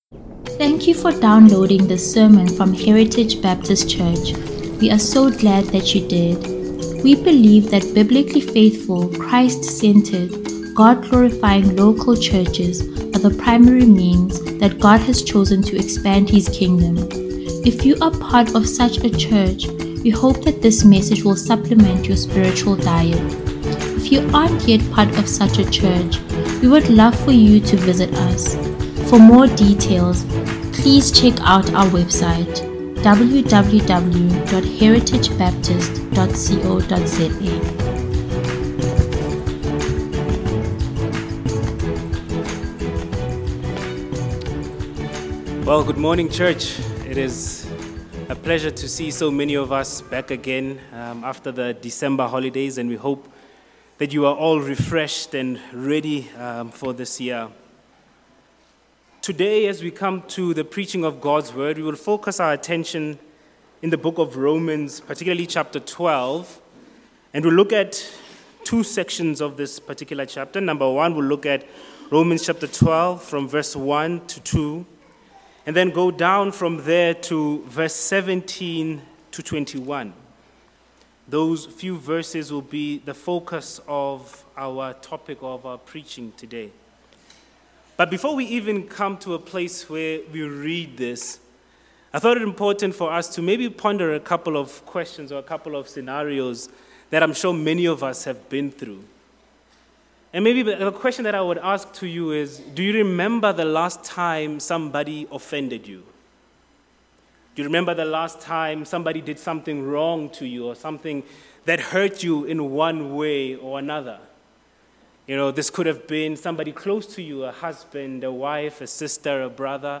Heritage Sunday Sermons